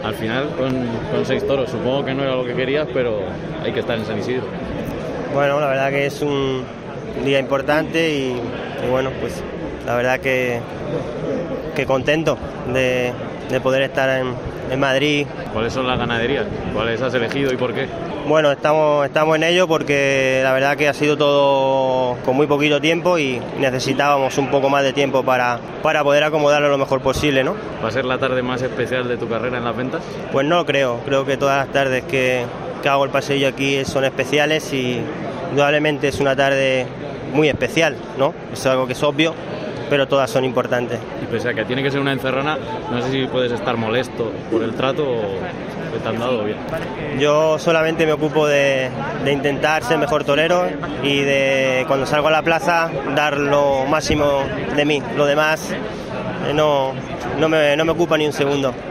El Albero de COPE habló con los principales protagonistas que acudieron a la gala de presentación de la Feria de San Isidro 2022